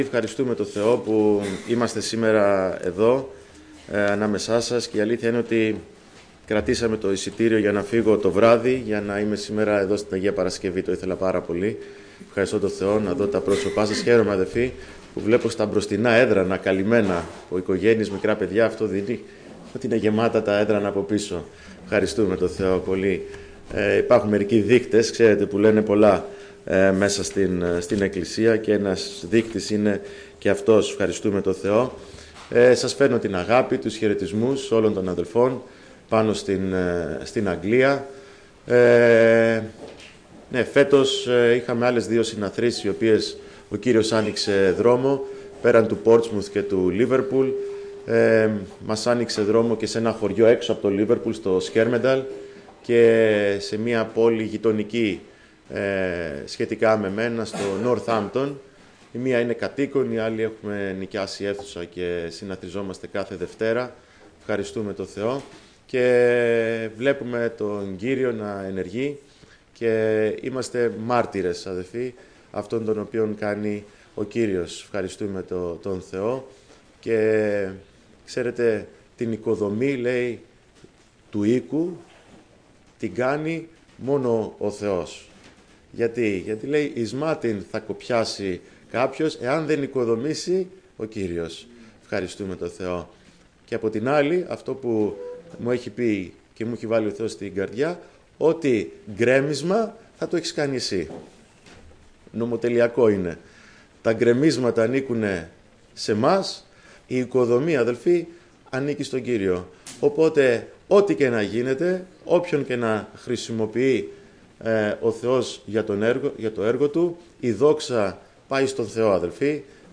Κήρυγμα Κυριακής